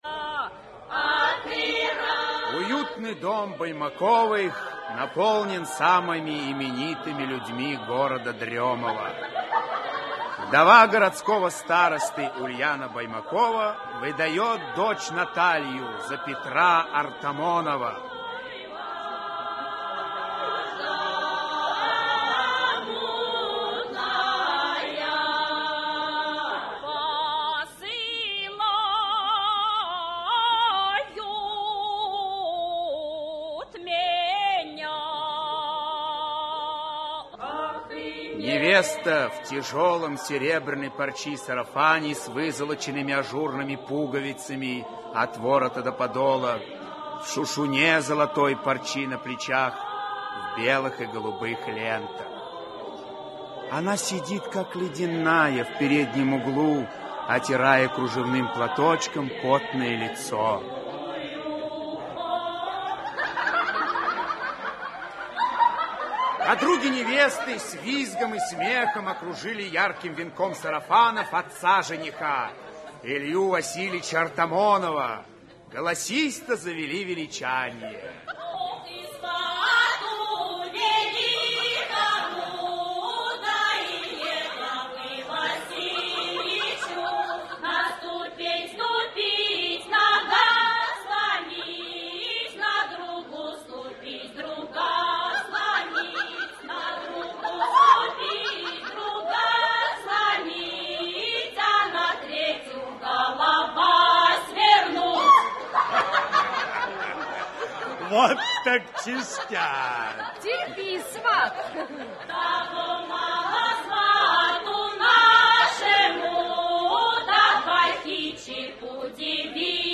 Aудиокнига Дело Артамоновых (спектакль) Автор Максим Горький Читает аудиокнигу Алексей Грибов.